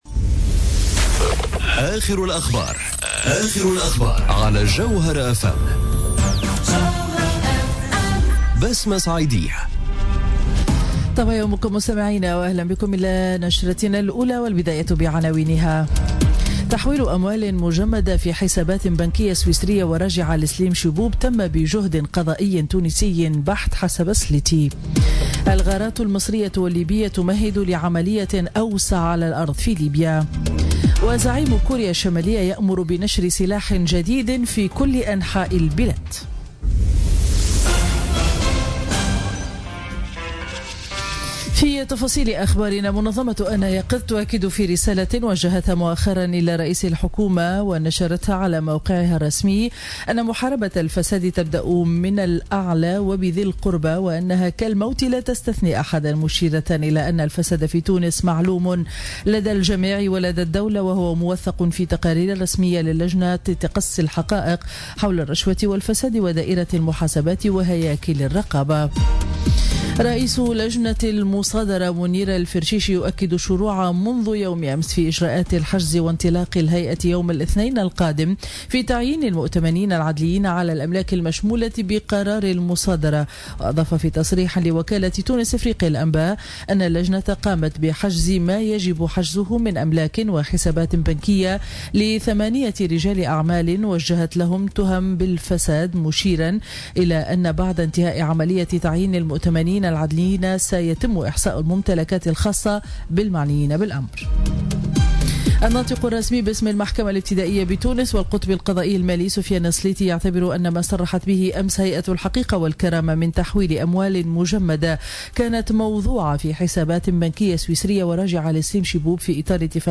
نشرة أخبار السابعة صباحا ليوم الاحد 28 ماي 2017